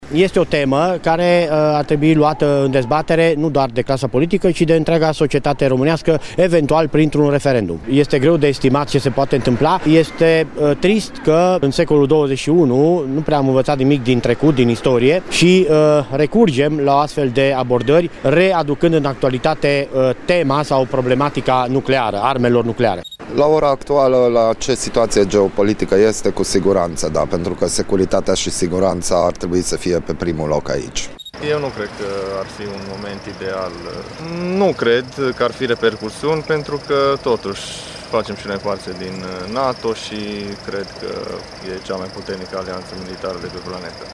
„Securitatea și siguranța ar trebui să fie pe primul loc aici”, consideră un alt bărbat